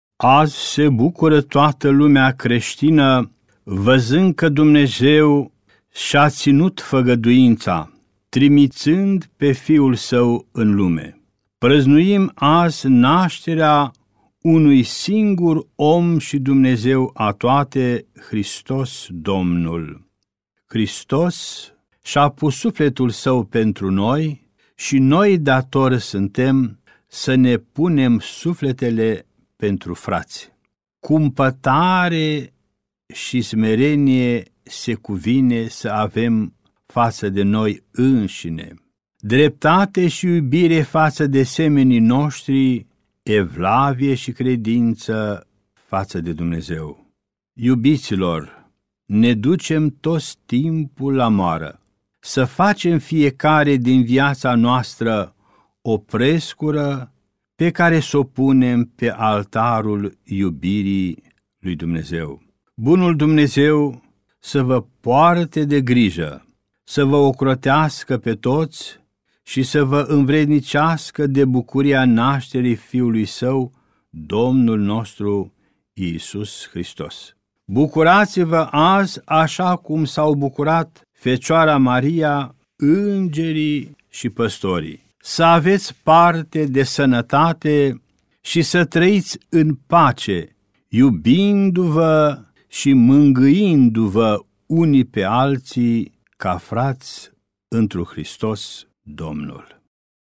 Mesajul de Crăciun al Înaltpreasfinției sale Ioan, Mitropolitul Banatului.